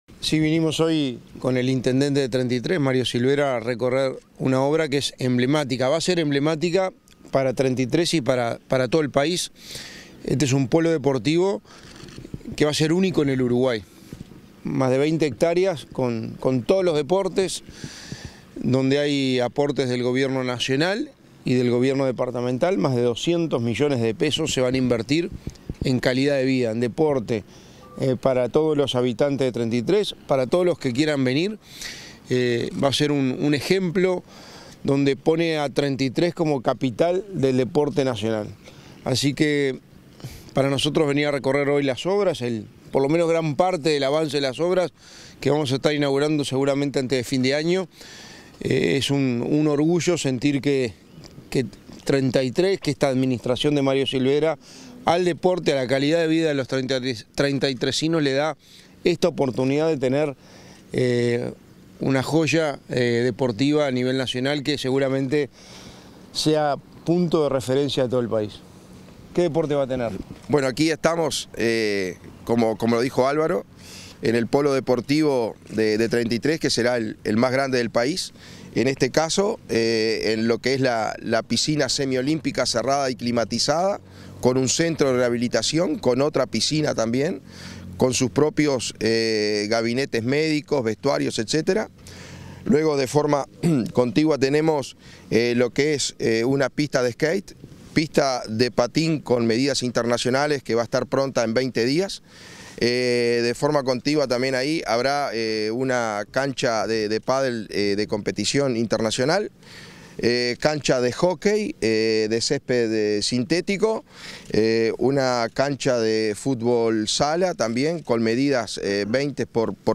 Entrevista al secretario de Presidencia y al intendente de Treinta y Tres